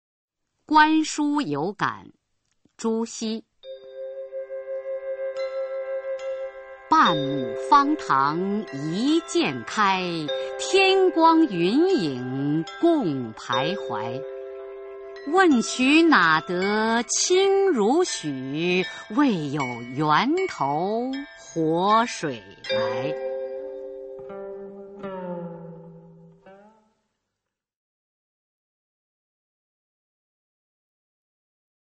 [宋代诗词诵读]朱熹-观书有感 宋词朗诵